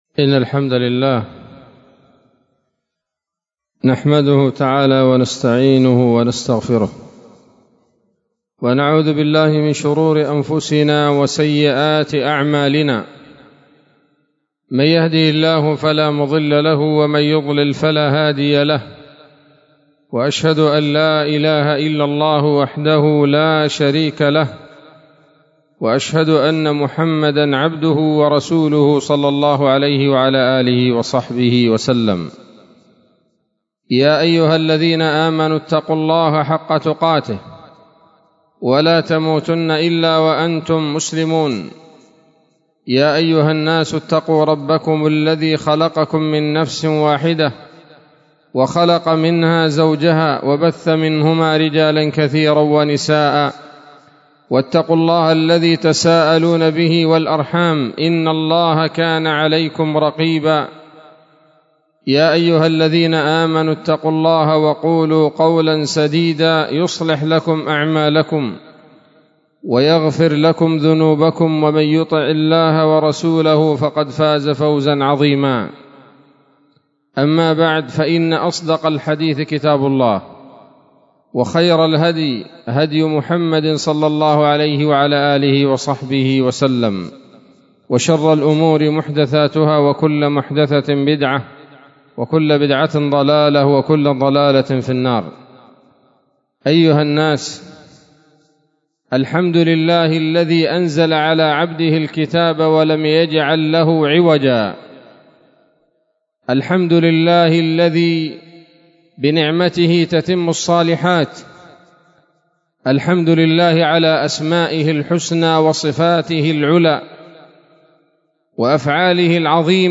خطبة جمعة بعنوان: (( بعض مواطن الحمد )) 12 شوال 1443 هـ، دار الحديث السلفية بصلاح الدين